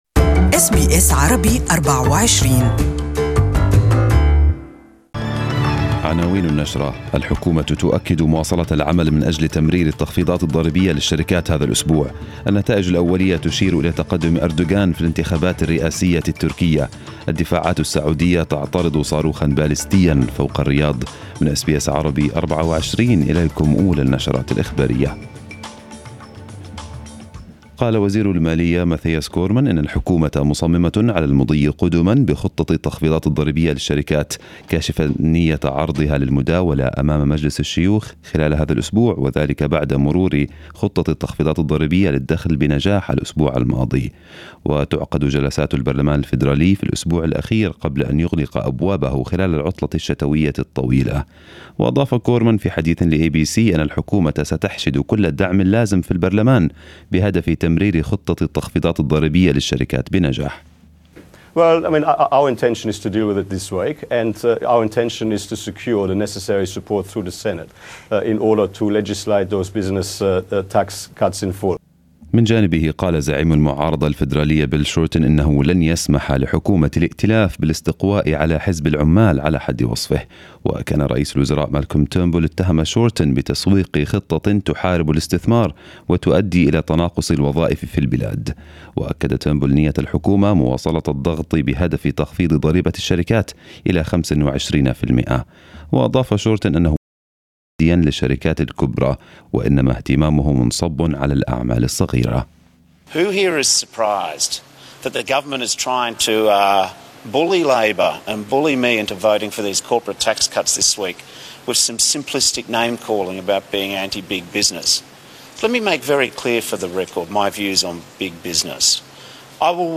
Arabic News Bulletin 25/06/2018